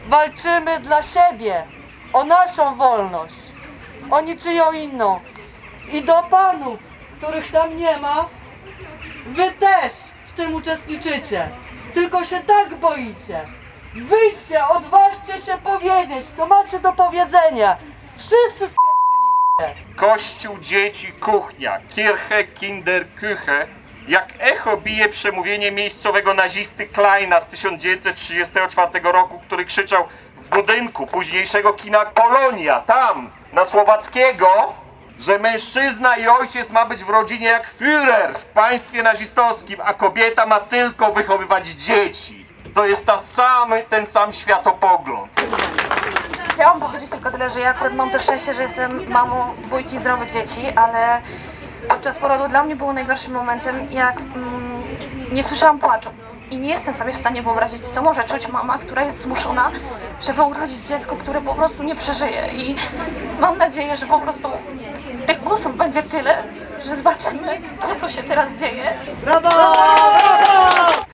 Chętni, którzy chcieli wyrazić swoje poglądy i odczucia, przekazywali sobie nawzajem megafon. Padły mocne słowa i odważne porównania, ale pojawiły się też łzy bezsilności.
Ludzie-pod-biurem-posla-clip-4.mp3